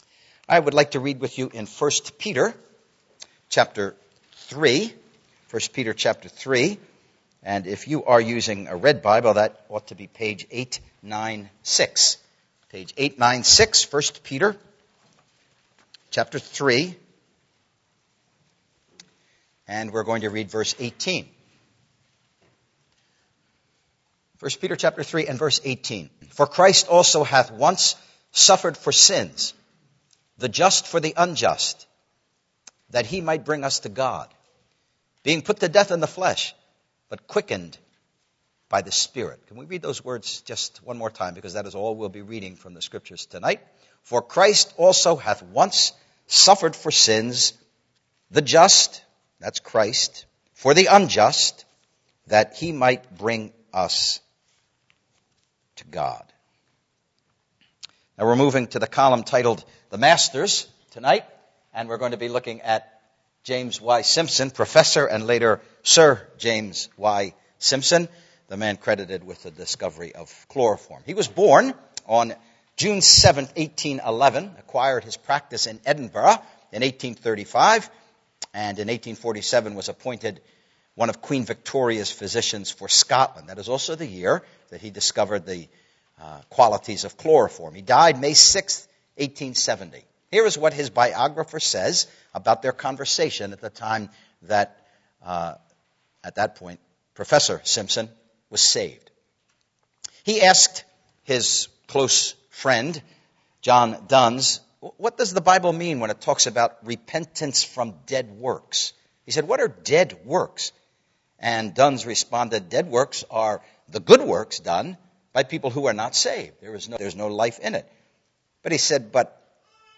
Series: The Impact of The Bible and Christianity on World History Service Type: Gospel Preaching